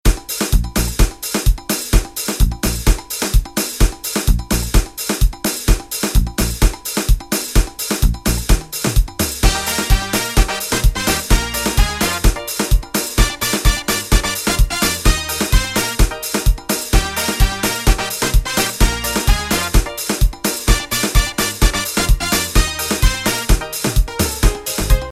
Soca/Calypso